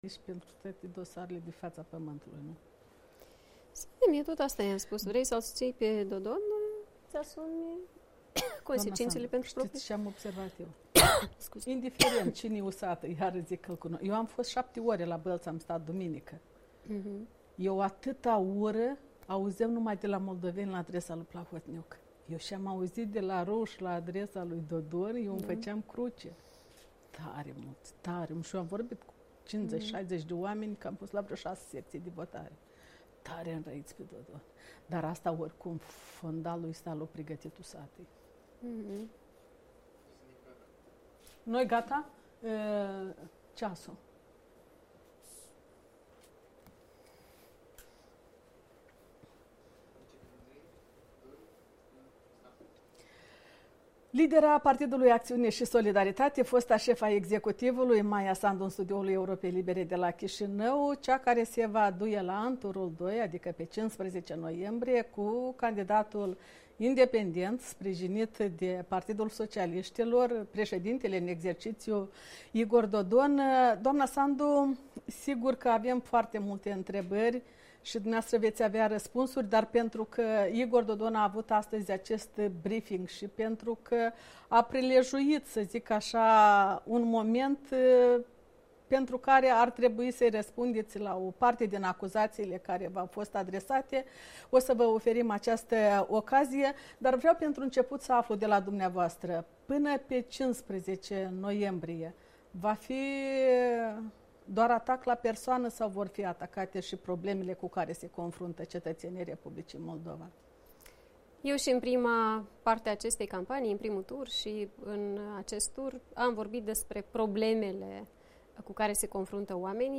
În studioul Europei Libere, fostul premier Maia Sandu, lidera Partidului Acțiune și Solidaritate, candidată la funcția de președinte al Republicii Moldova.
Interviu cu Maia Sandu